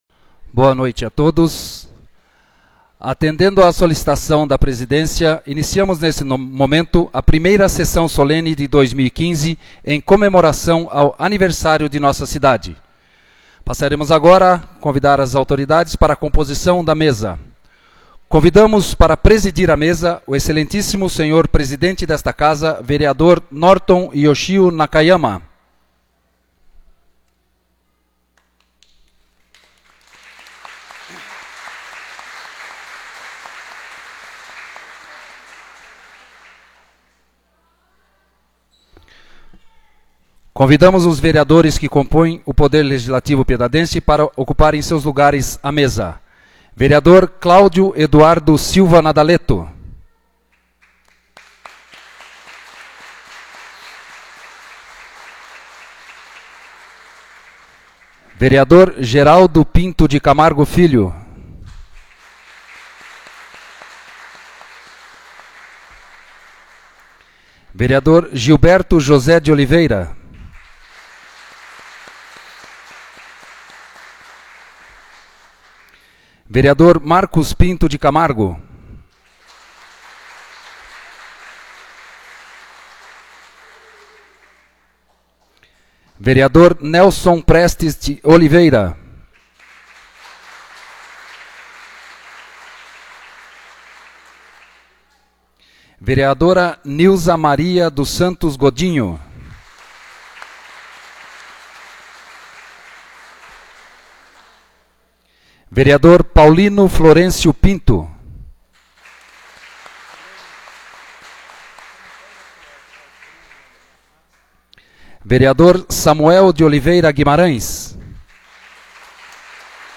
1ª Sessão Solene de 2015